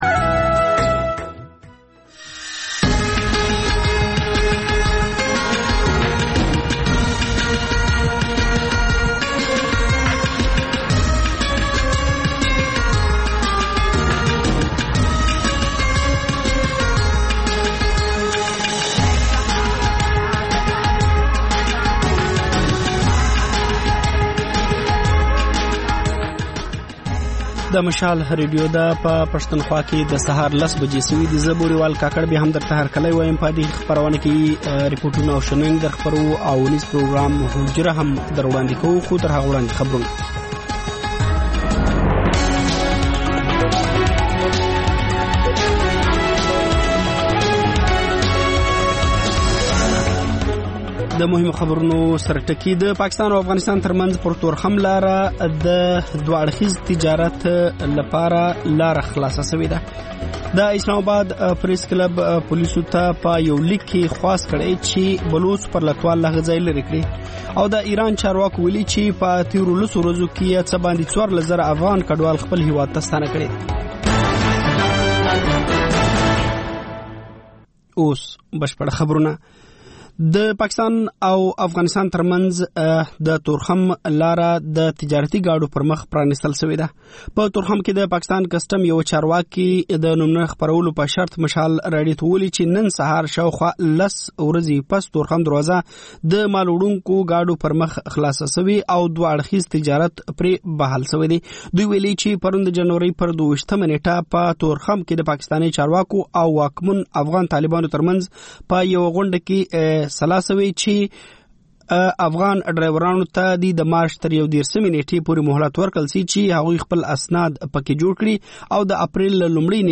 په دې خپرونه کې تر خبرونو وروسته بېلا بېل رپورټونه، شننې او تبصرې اورېدای شﺉ. د خپرونې په وروستیو پینځلسو دقیقو یا منټو کې یوه ځانګړې خپرونه خپرېږي.